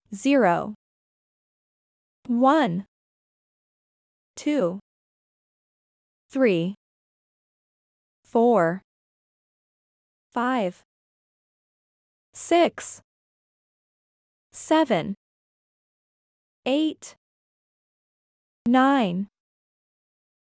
0Zero[ˈzɪə.rəʊ]нуль
Нижче можете прослухати, як читаються та вимовляються англійські цифри.